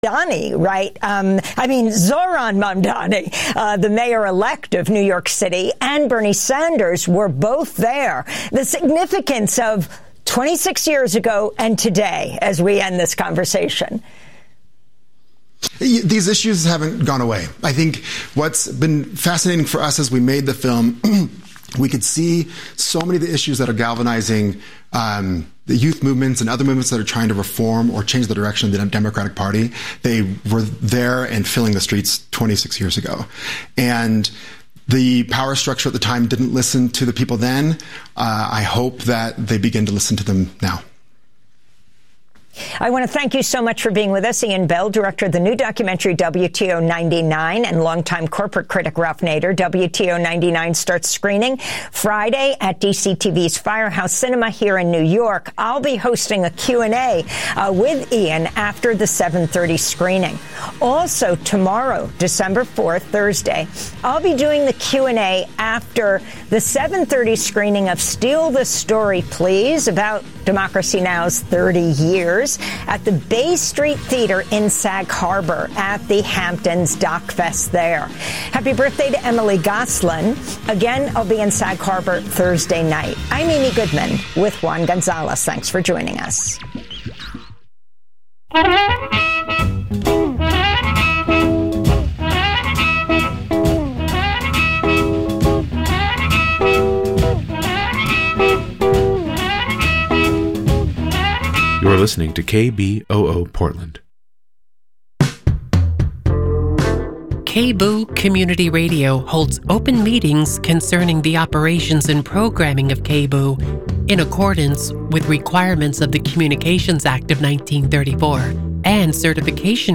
Progressive Voices Talk Radio